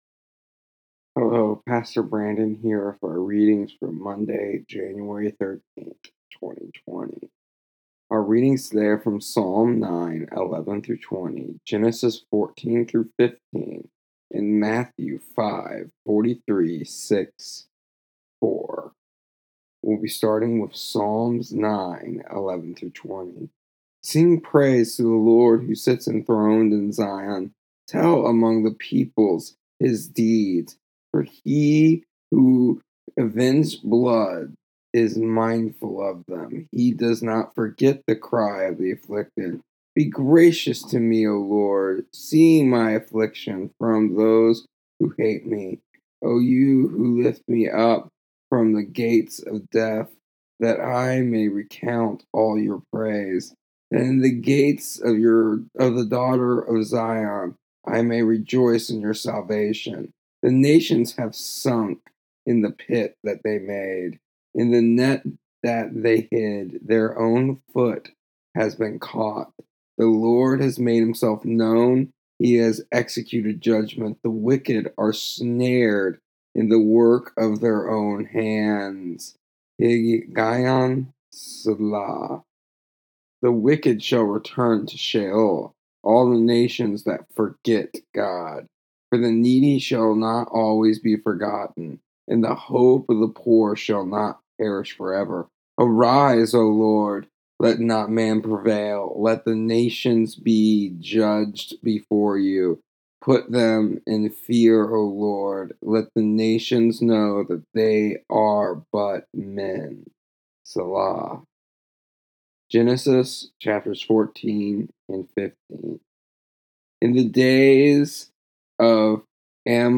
I apologize for the names. My tongue just couldn't get out the sounds today.
Here are the audio version and daily devotional for, January 13th, 2020, of our daily reading plan.